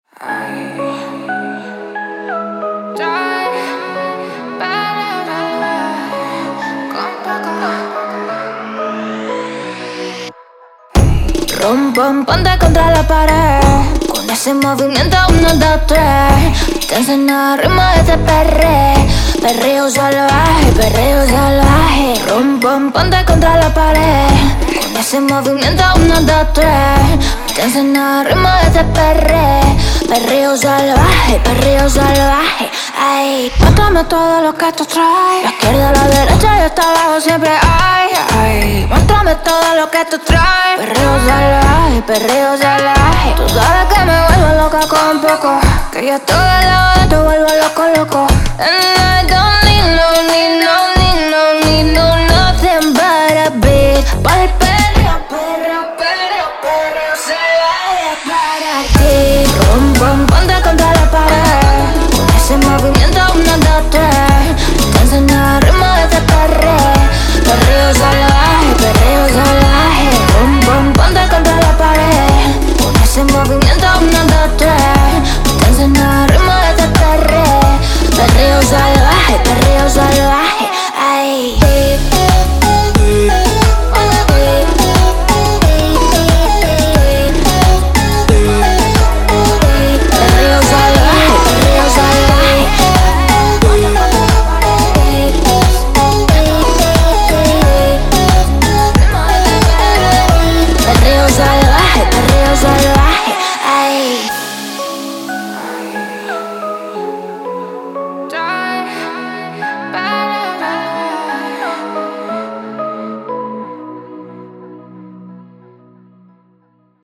立即为现代拉丁流行音乐赢得5杯西班牙风味的无伴奏合唱！
预计有5条完整的无伴奏曲调，可分为ad-lib，后人声，哑音，和声和主音，可以轻松将其拖放到DAW中。
所有人声文件均为24bit .wav文件，并由音频工程师进行后期处理和清理。